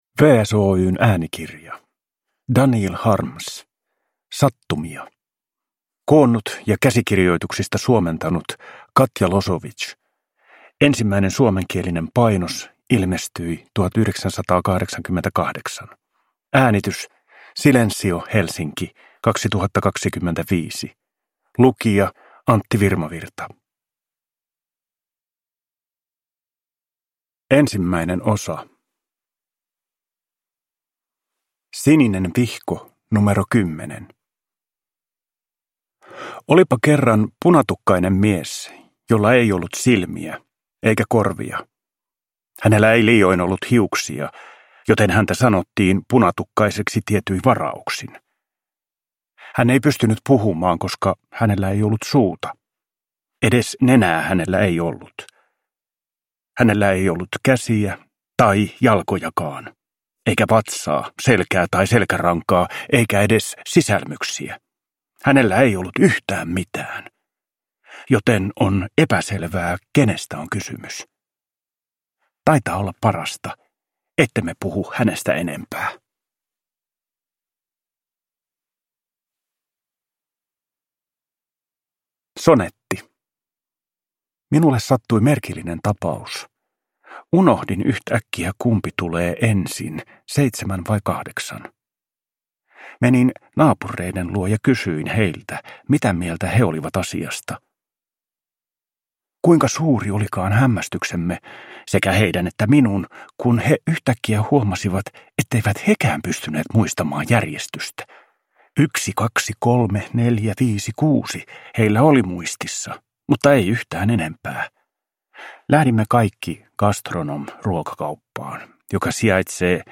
Sattumia – Ljudbok